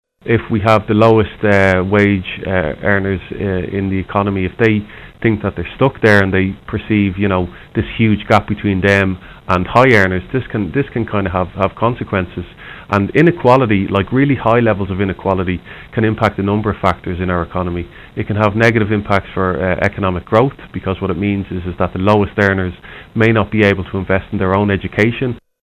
News, Audio, Playback, Top Stories